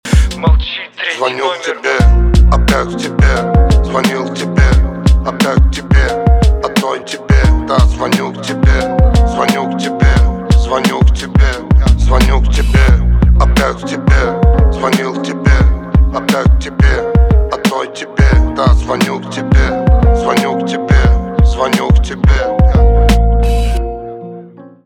рэп
русский рэп
басы , грустные , гитара